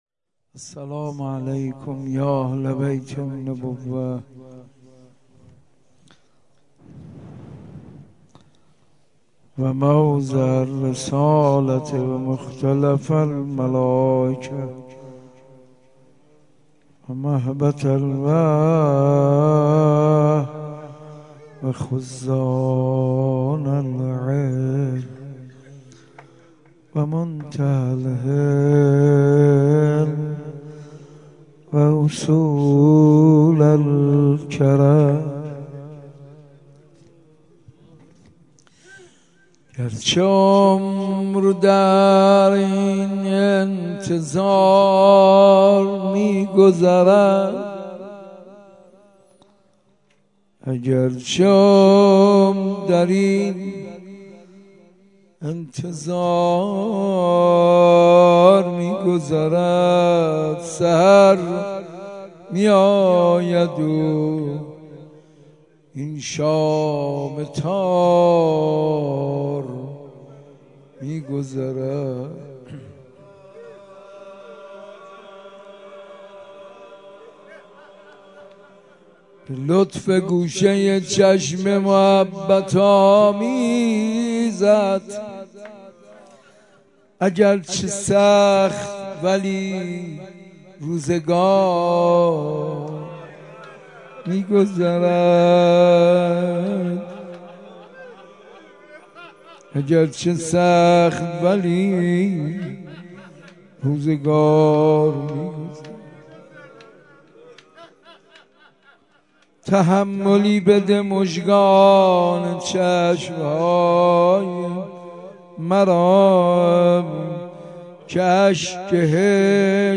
دهه ی دوم صفرالمظفر 1393 | حسینیه ی مرحوم چمنی | حاج منصور ارضی :: خیمه - پایگاه تخصصی اشعار آیینی